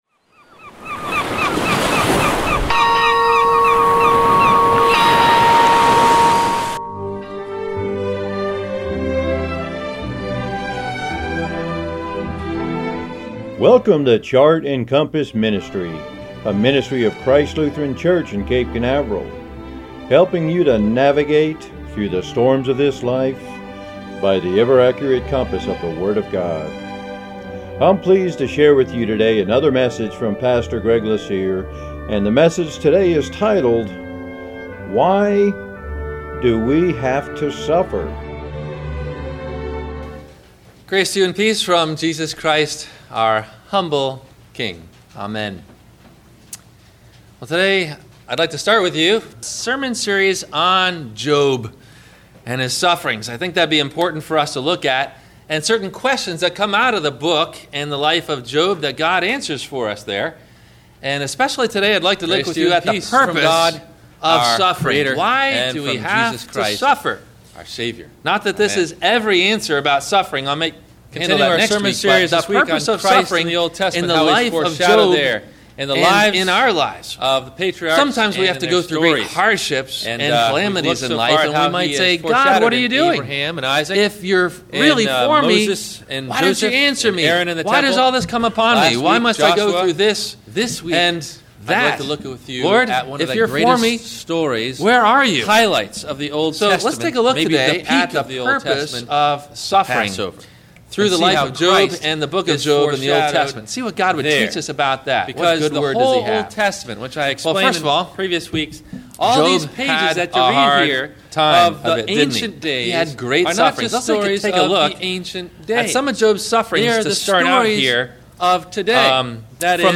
Have a Comment or Question about the Sermon?